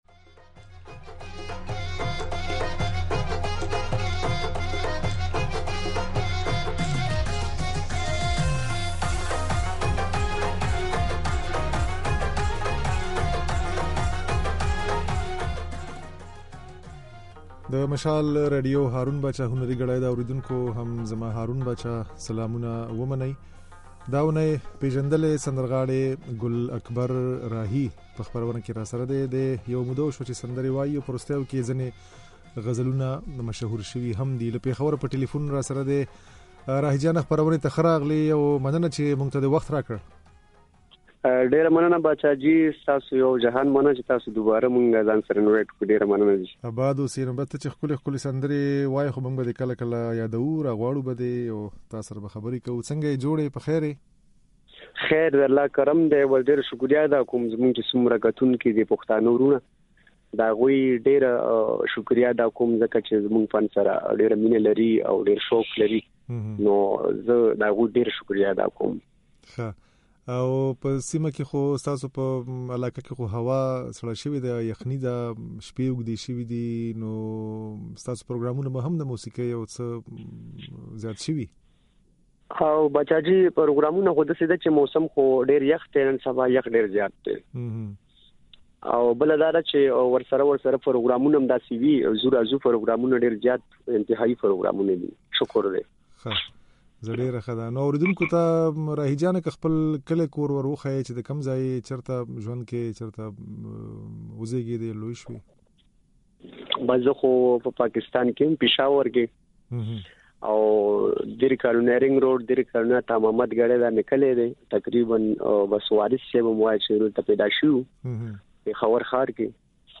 د ده خبرې او ځينې سندرې يې د غږ په ځای کې اورېدای شئ.